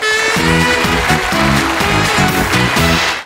schweinchen.ogg